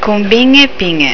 (pronuncia)   funghi (Colombine)